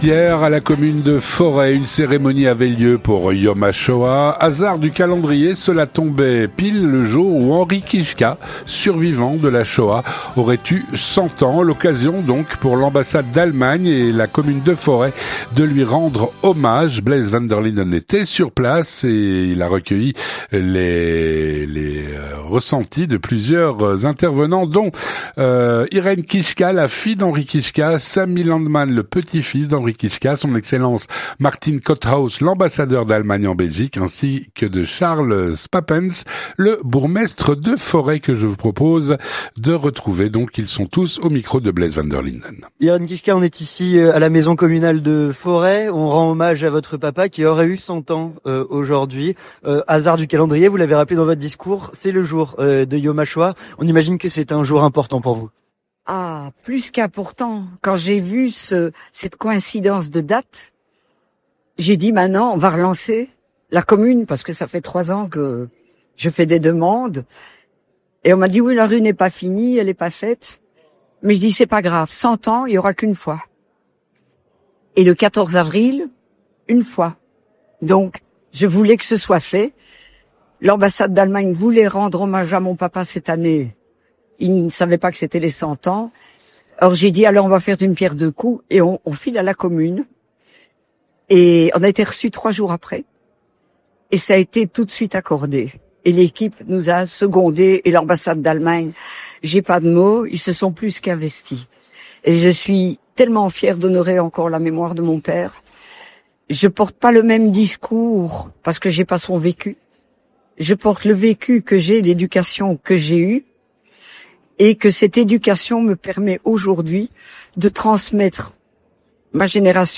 Hier, à la commune de Forest, une cérémonie avait lieu pour Yom Hashoah.